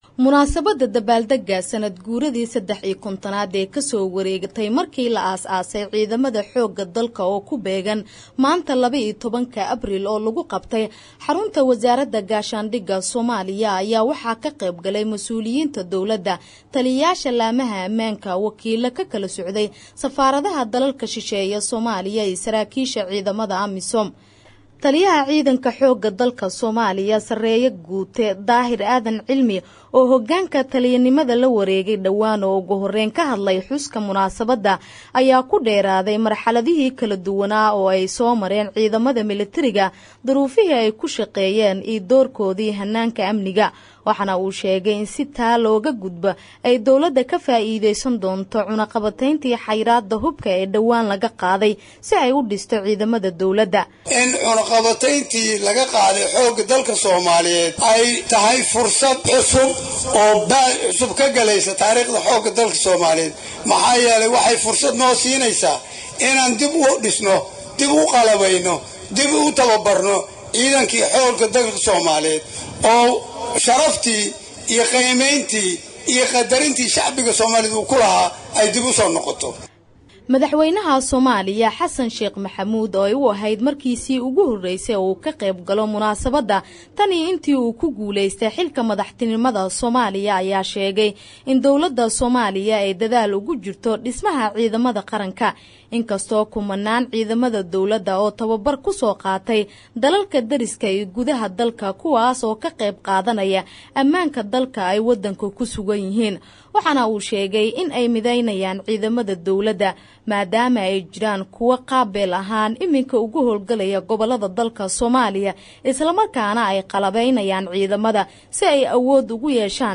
Warbixinta Dabaaldagga Ciidanka Xoogga Dalka